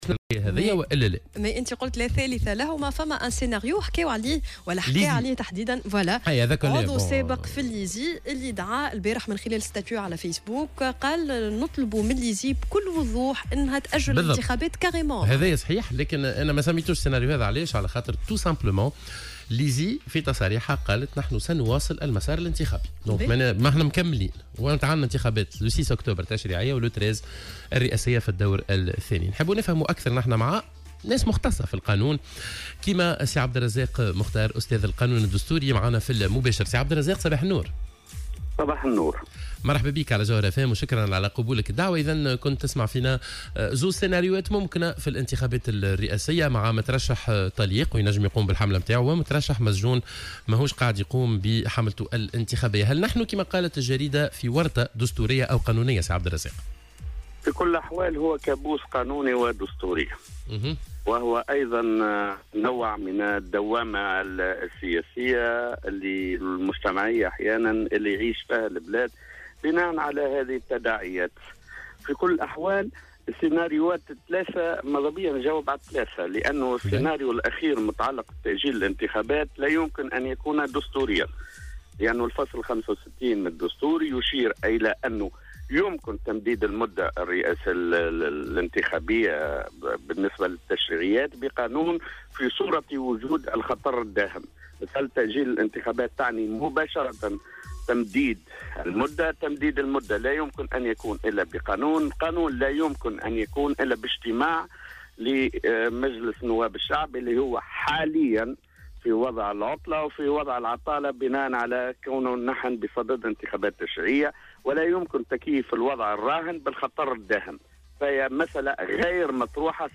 وقال في مداخلة هاتفيّة مع "صباح الورد" على "الجوهرة أف أم" إن هناك 3 سيناريوهات محتملة بخصوص مصير الانتخابات الرئاسية المبكرة.